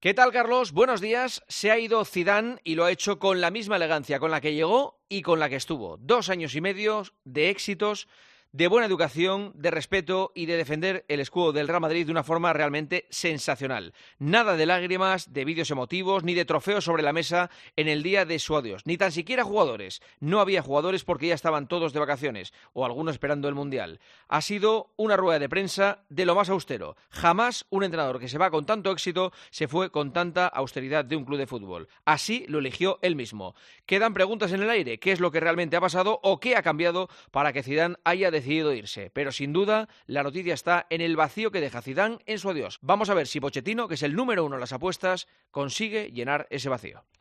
AUDIO: Escucha el comentario del director de 'El Partidazo de COPE', Juanma Castaño, en 'Herrera en COPE'